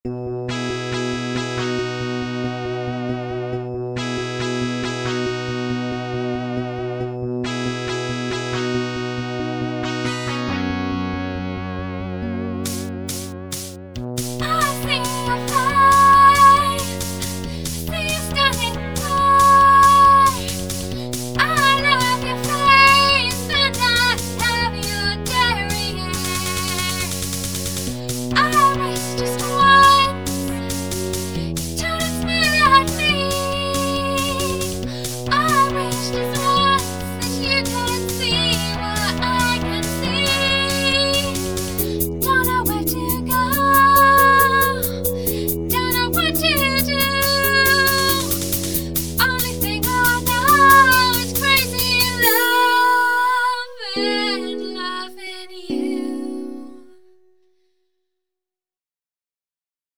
More like extreme teeny bopperish pop, but hey, they can play their own instruments!!
Crazy Loving You (midi version)